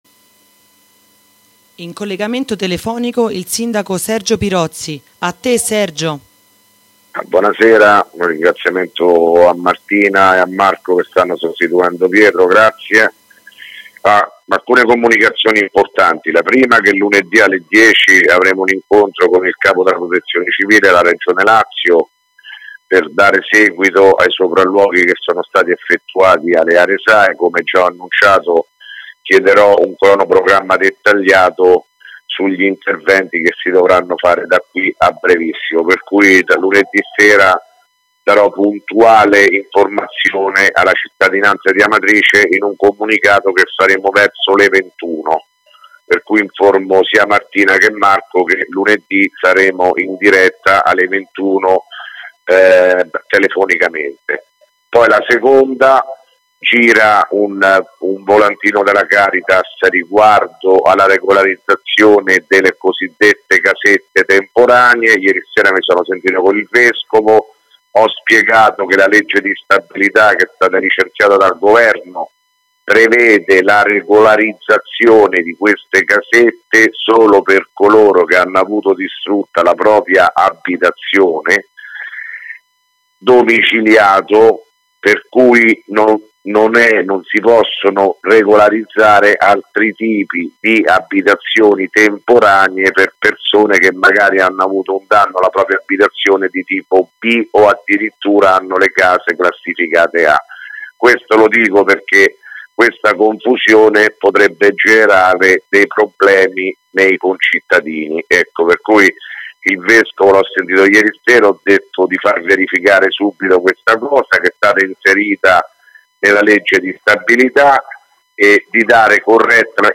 Di seguito il messaggio audio del Sindaco Sergio Pirozzi del 6 gennaio 2018